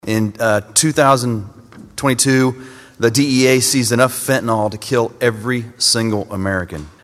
Senator Jeff Reichman, a Republican from Montrose, says fentanyl is 50 times stronger than heroin and up to 100 times stronger than morphine.